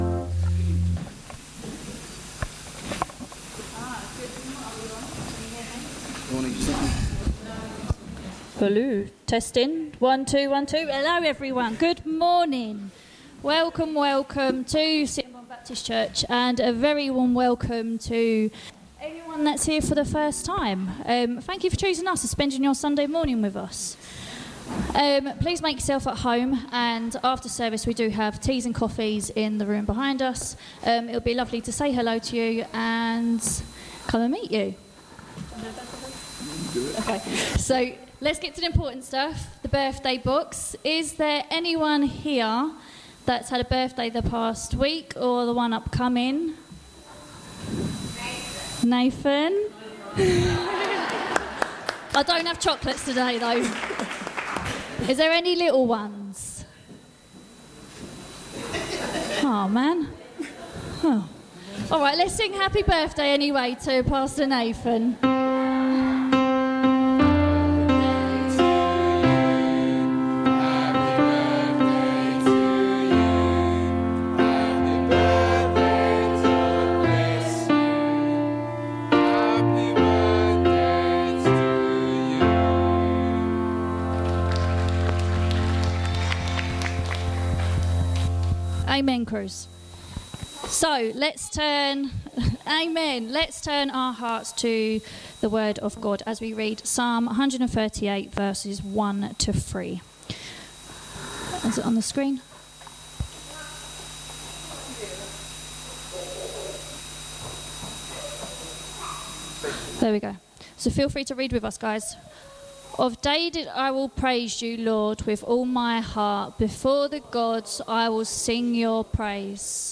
Morning service - Sittingbourne Baptist Church
Service Audio